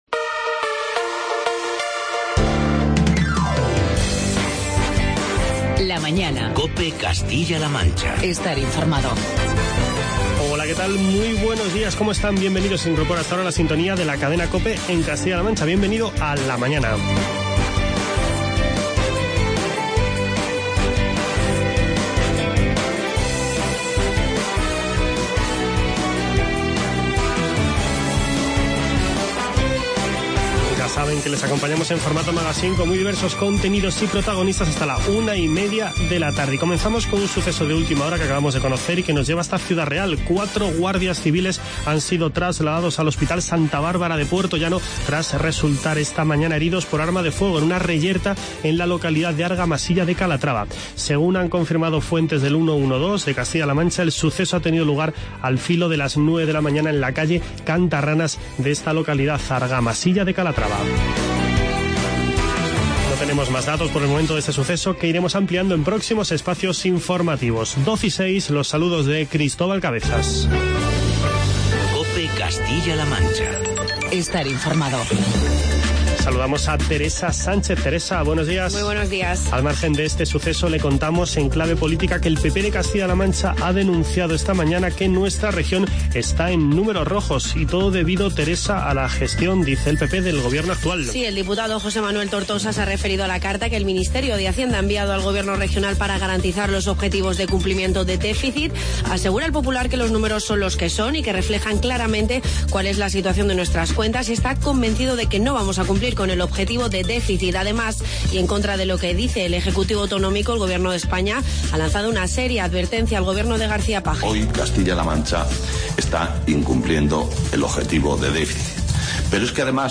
Escuche las entrevistas con Orlena de Miguel, portavoz de Ciudadanos en Castilla-La Mancha, y Soledad de Frutos, alcaldesa de La Puebla de Montalbán.